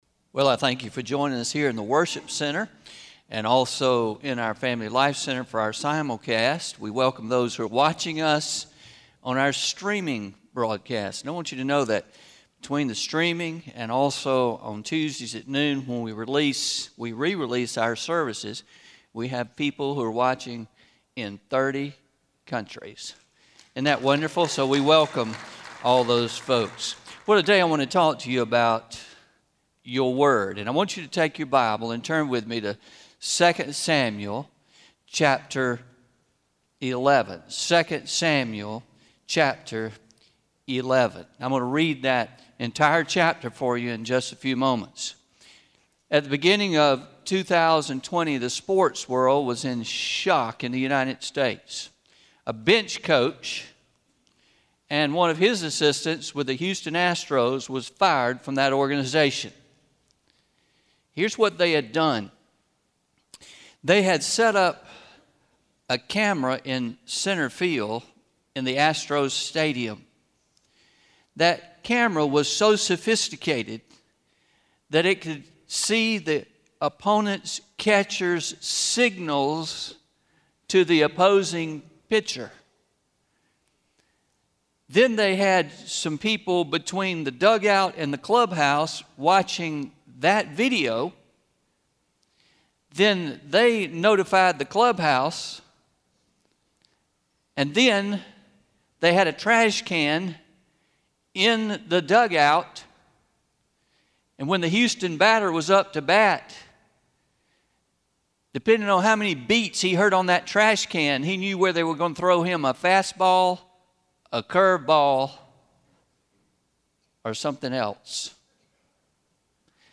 11-01-20am – Sermon – What about your Word – Traditional